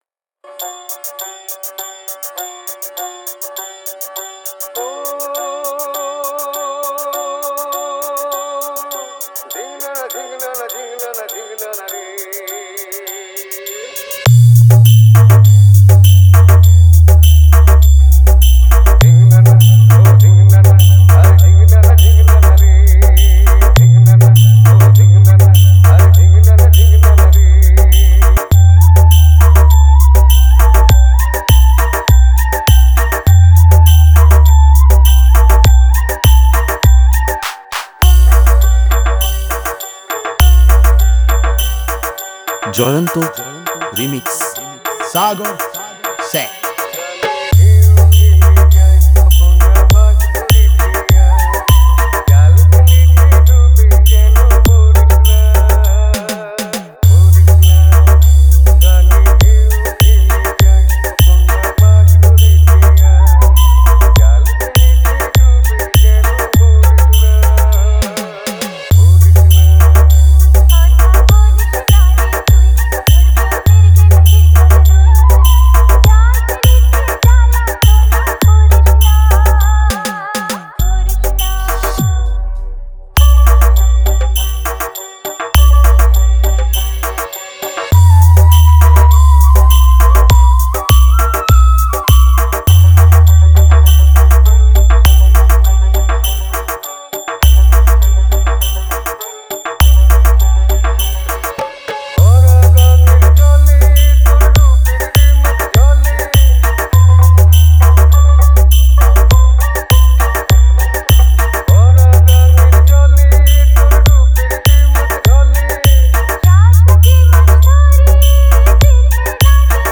পুজোর স্পেশাল গান